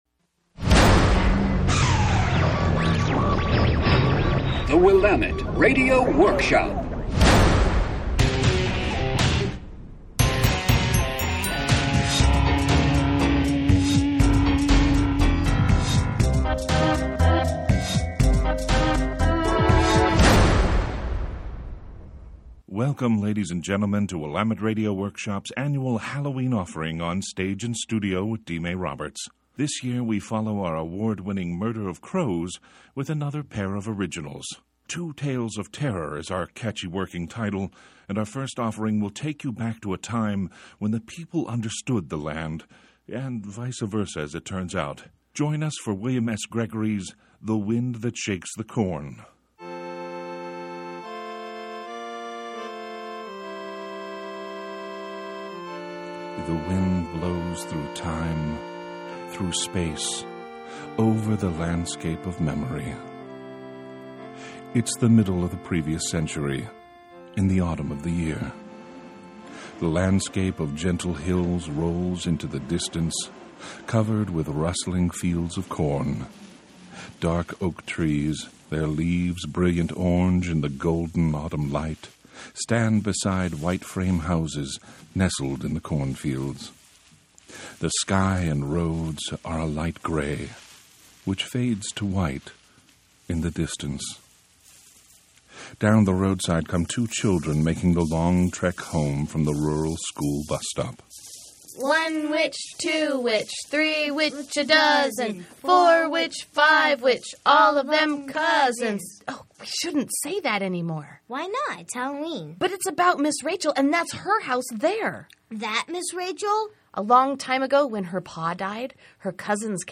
HURF (11.8 MB MP3, 28:00) The Wind That Shakes the Corn In honor of William S. Gregory’s very successful adaptation of The Confessions of Dr. Jekyll and Mr. Hyde , we present here a short piece from “Two Tales of Terror,” broadcast on KBOO. We hope you enjoy this offering in the memory of Halloween, the story of a time when the people knew the land (and vice versa, as it turns out).